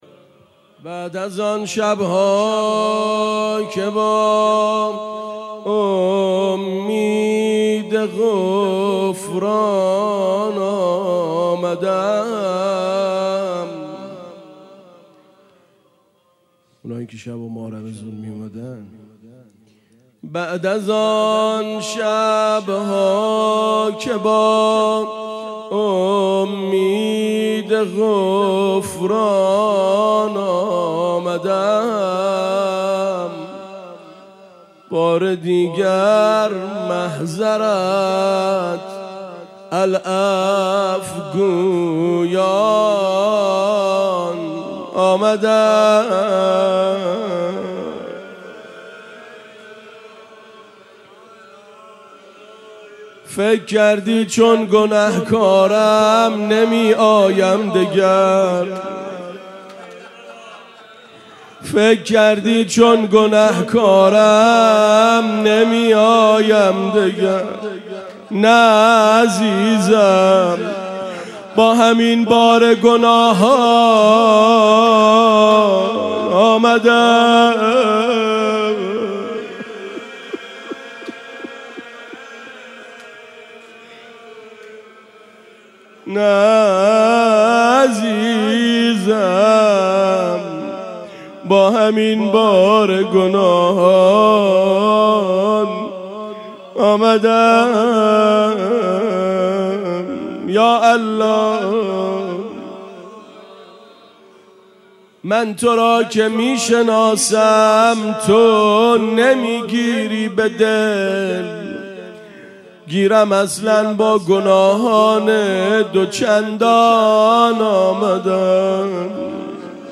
خیمه گاه - روضةالشهداء - زمزمه بعد از آن شبها که با امید غفران آمدم
مراسم قرائت دعای عرفه